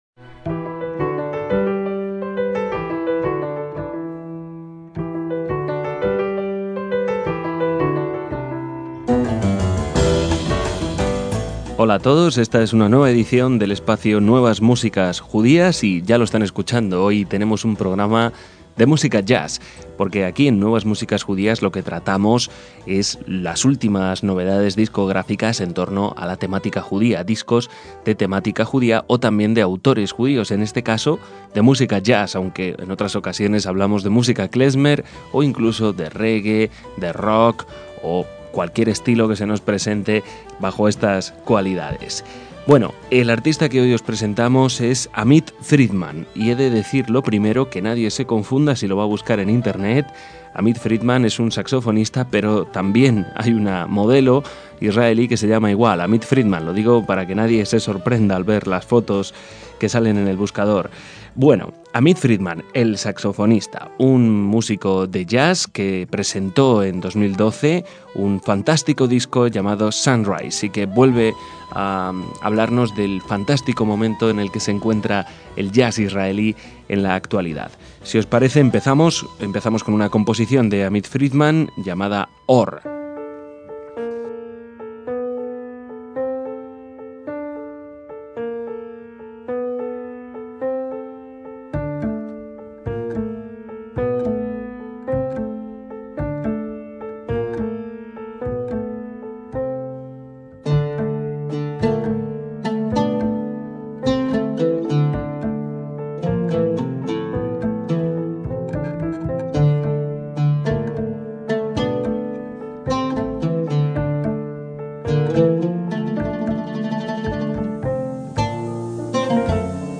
junto a un sexteto de jazz y un cuarteto de cuerdas